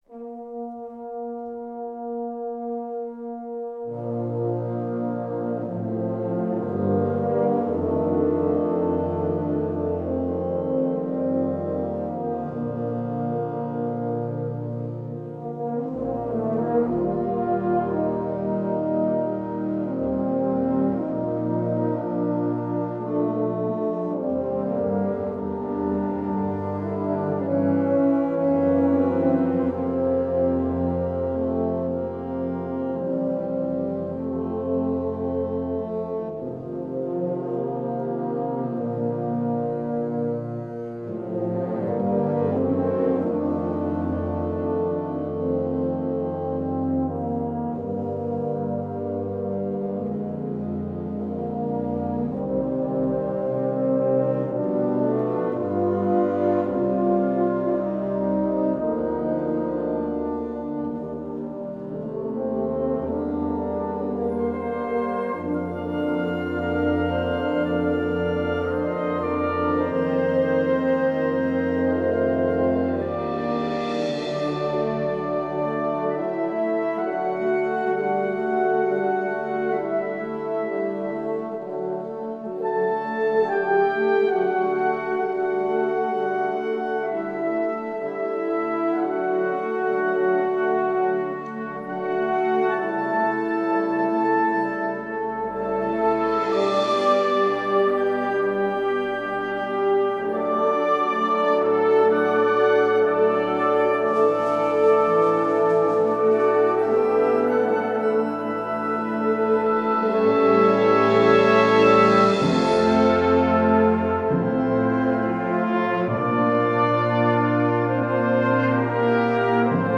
Gattung: Kirchenmusik
Besetzung: Blasorchester
ein bewegender Choral im spätromantischen Stil